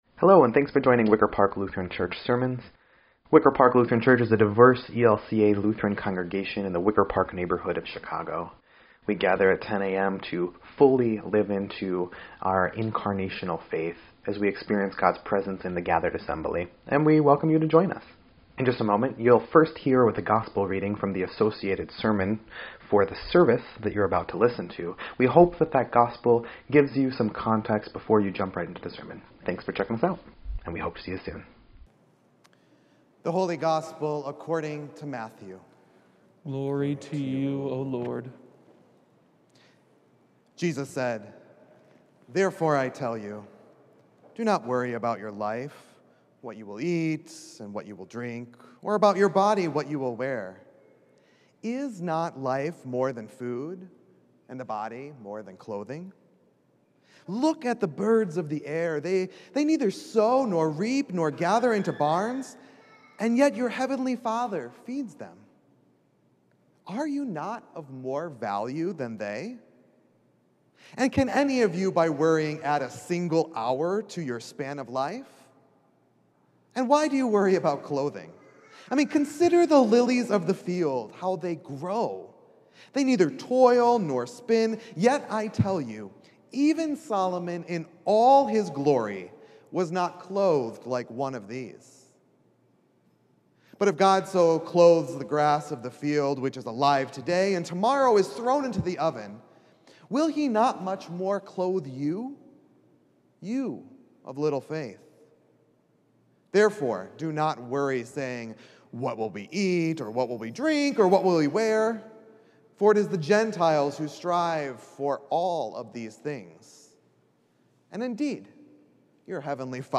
10.3.21-Sermon_EDIT.mp3